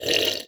Minecraft Version Minecraft Version snapshot Latest Release | Latest Snapshot snapshot / assets / minecraft / sounds / mob / wandering_trader / drink_milk2.ogg Compare With Compare With Latest Release | Latest Snapshot
drink_milk2.ogg